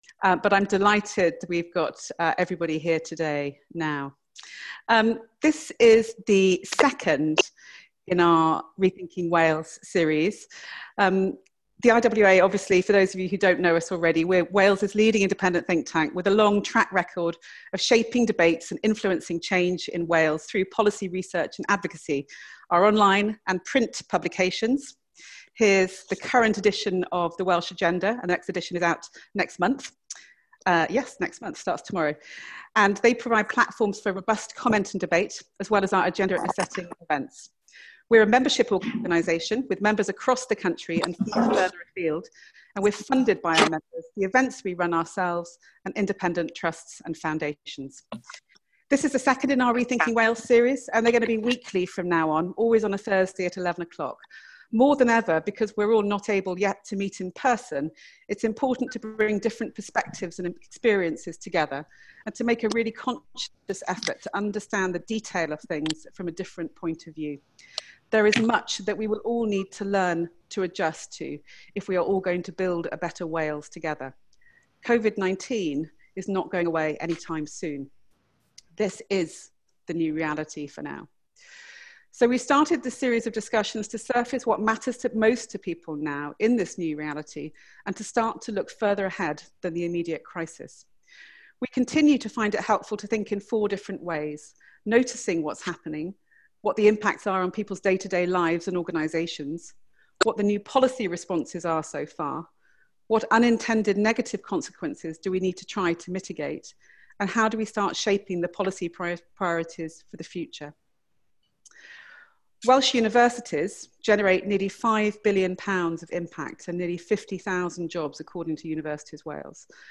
This week the topic of the discussion is Higher Education and the Economy and we will be exploring two questions: What are the immediate problems Wales faces as a result of Covid-19?